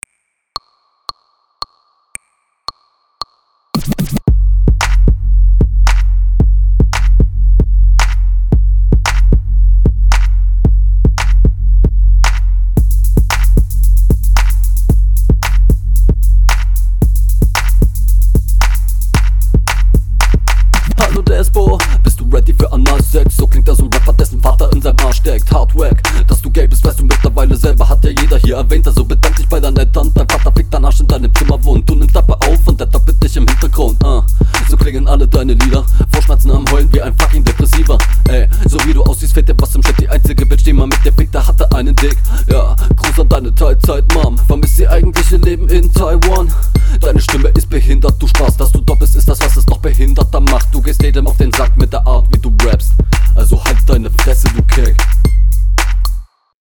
Beat ist ganz gechillt . kommst auch flowlich ganz cool auf dem Beat , kommt …
Die erste Line klingt irgendwie komisch :D Du bis stimmlich ganz interessant unterwegs, flowtechnisch find …